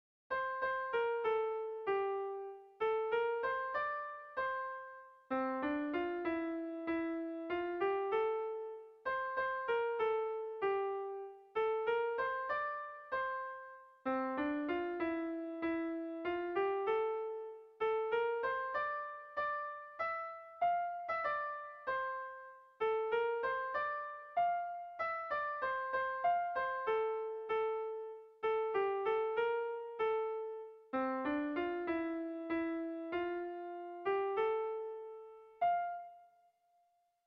Melodías de bertsos - Ver ficha   Más información sobre esta sección
Zortziko handia (hg) / Lau puntuko handia (ip)
ABDE